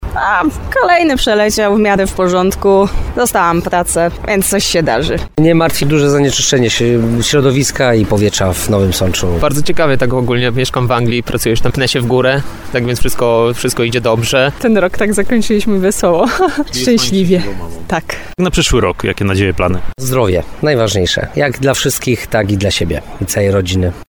Sądeczanie przywitali już nowy rok, a my zapytaliśmy jak minął im ubiegły, 2022 rok.